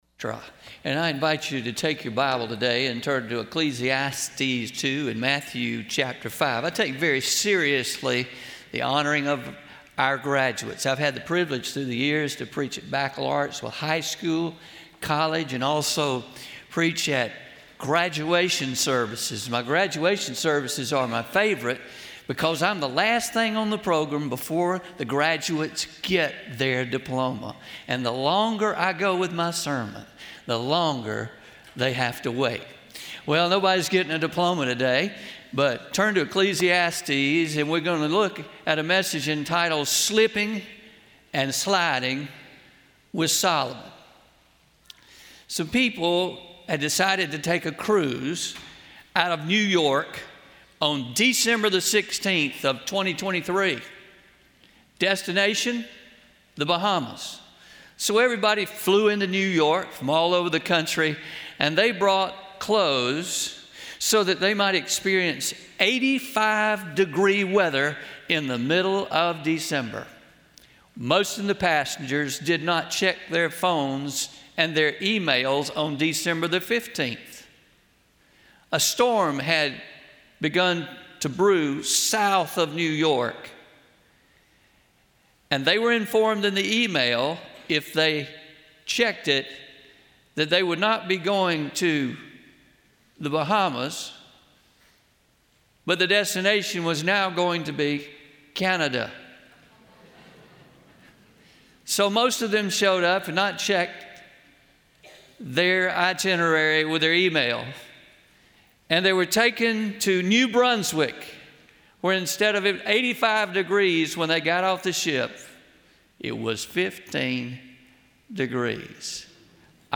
04-28-24am Sermon – Slipping and Sliding with Solomon